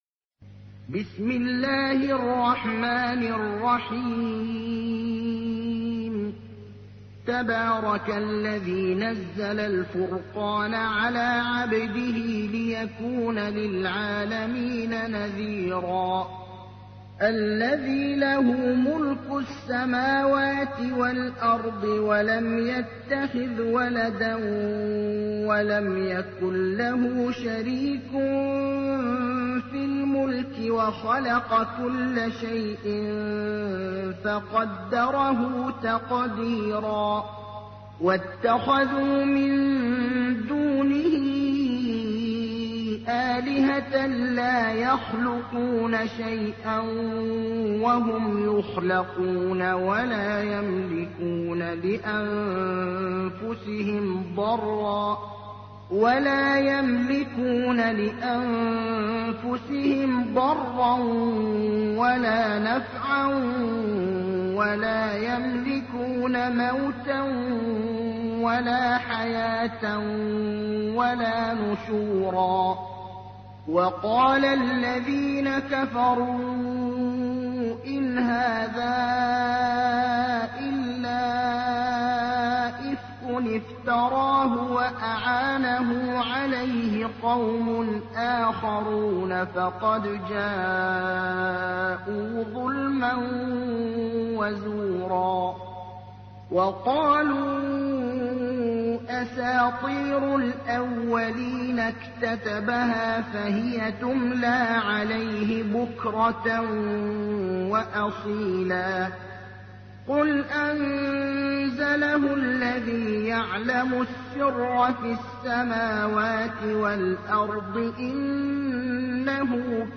تحميل : 25. سورة الفرقان / القارئ ابراهيم الأخضر / القرآن الكريم / موقع يا حسين